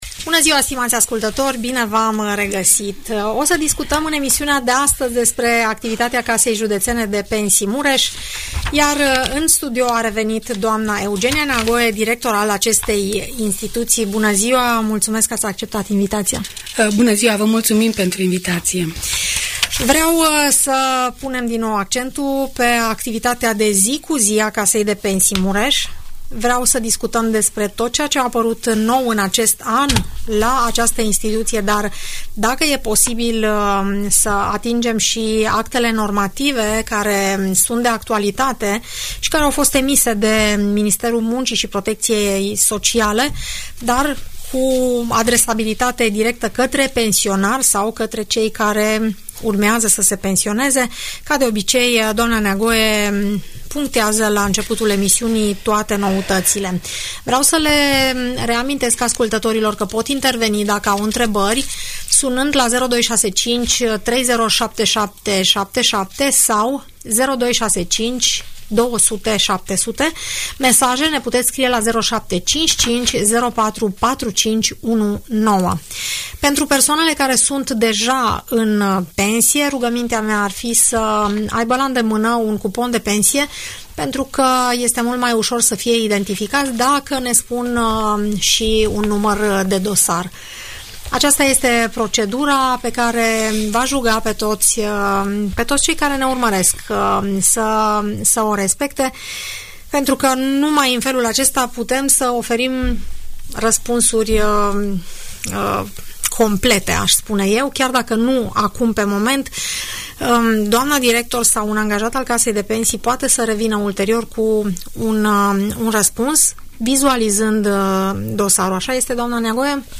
Audiență radio cu întrebări și răspunsuri pe tema pensiilor sociale de stat în emisiunea „Părerea ta” de la Radio Tg Mureș.